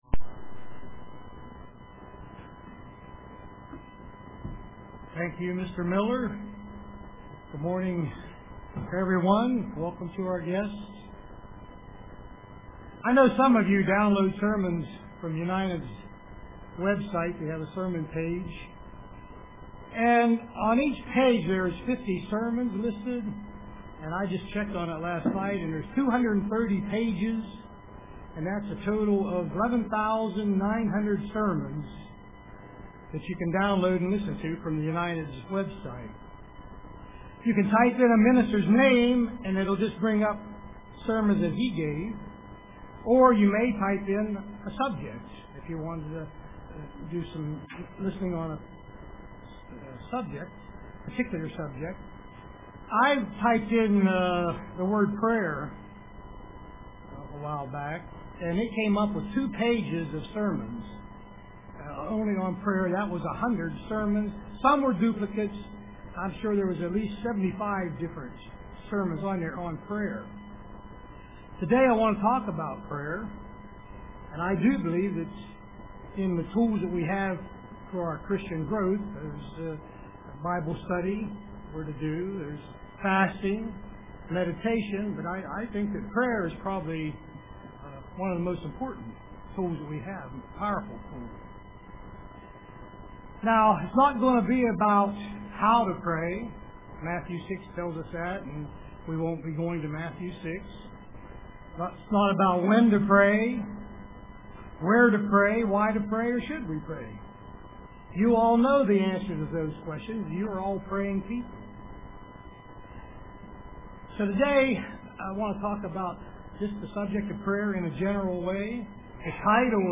Print Prayer UCG Sermon Studying the bible?